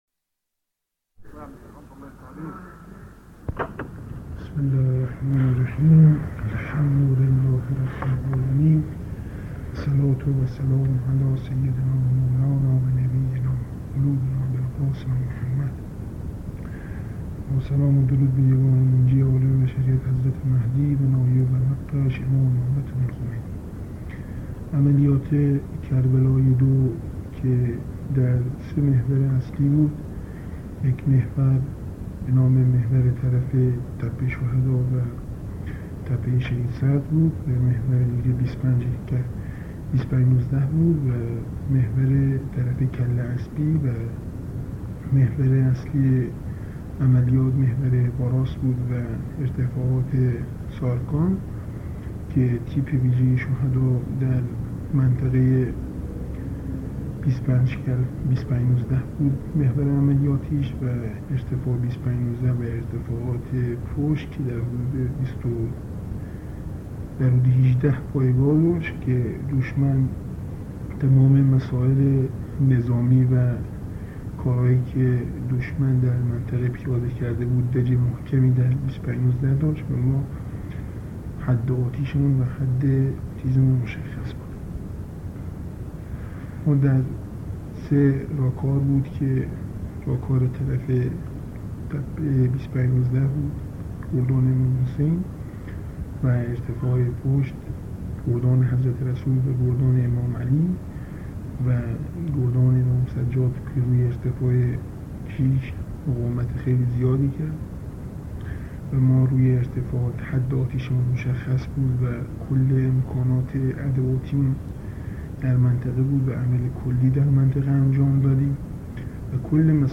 صدای ماندگار/ مصاحبه